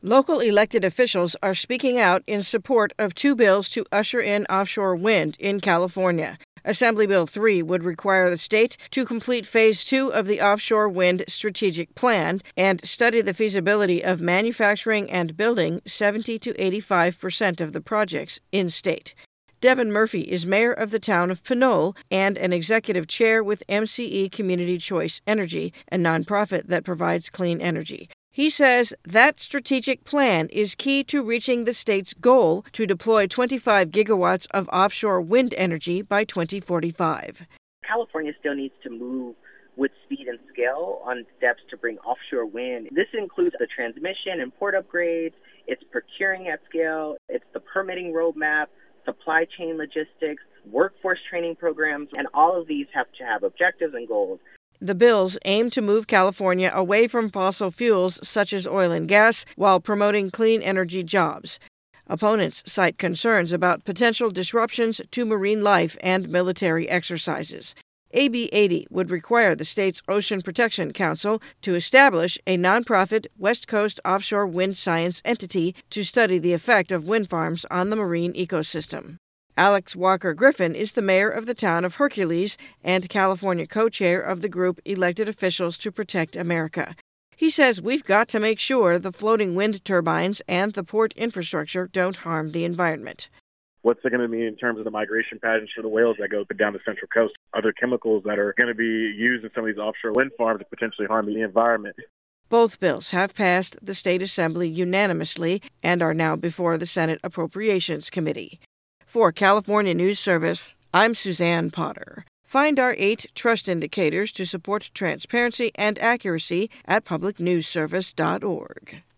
RADIO
The following radio reports are done in partnership with Public News Service.